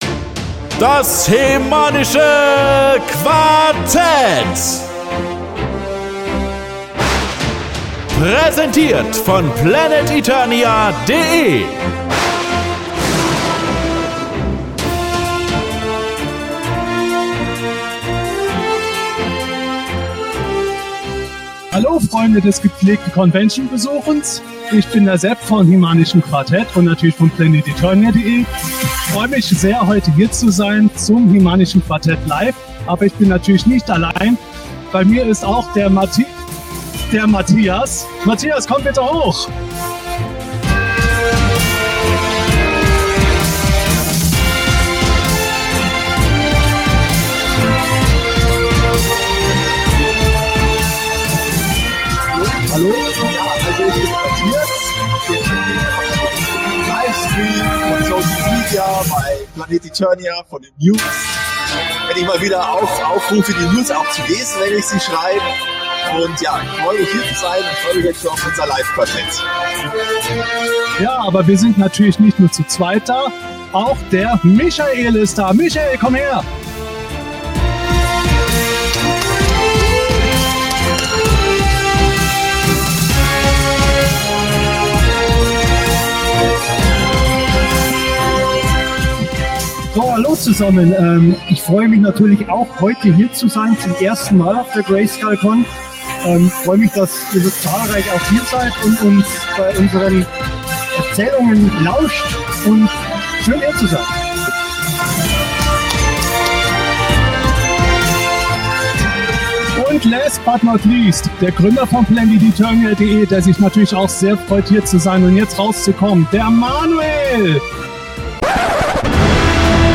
Herzlich willkommen zum HE-MANischen Quartett - LIVE von der Grayskull Convention 2021. Unsere 211. Ausgabe haben wir vor Live-Publikum im Freien auf dem Con-Gelände aufgenommen. Wir bitte euch deshalb, die etwas schlechtere Tonqualität zu entschuldigen.